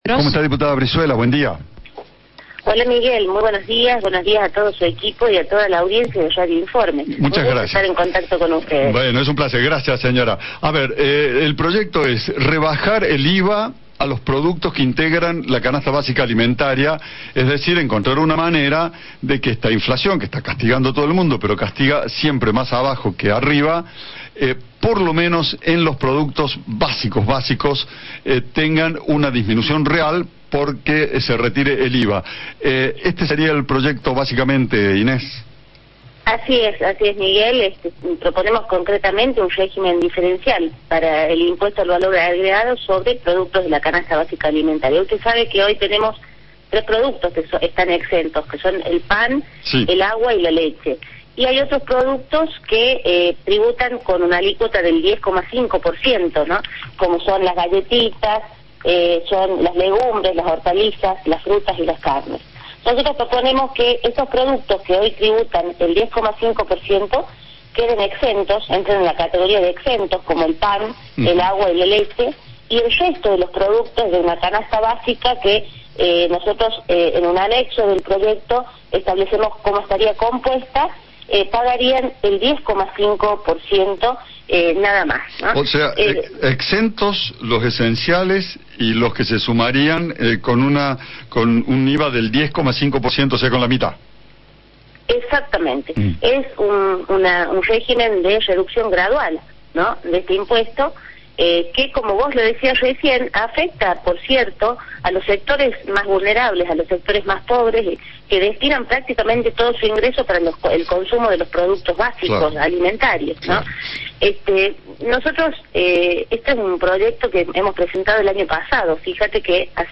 Inés Brizuela y Doria, diputada nacional, por Cadena 3
«El proyecto apunta a que los que tributan el 10,5% queden exentos y el resto de los productos pagaría el 10,5%», dijo a radio Cadena 3 Argentina.